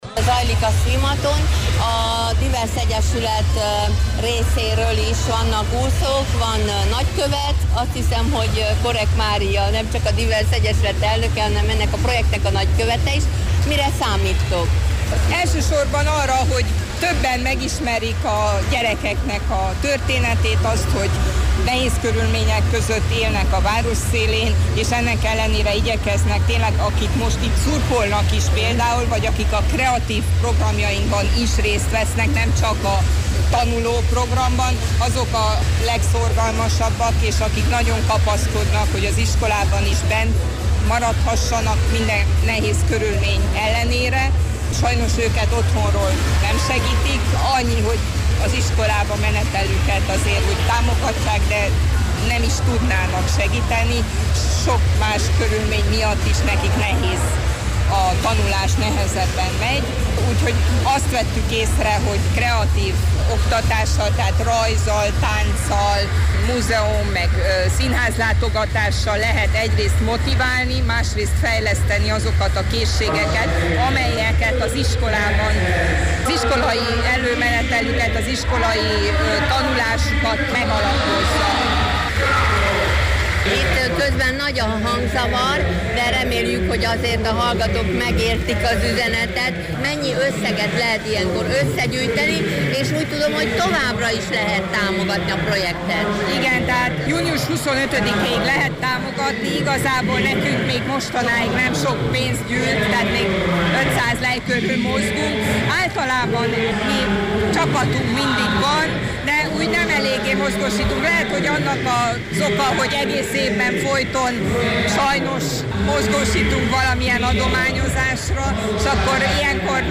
Múlt szombaton ott voltunk a marosvásárhelyi olimpiai méretű uszodában, ott készült e riportunk.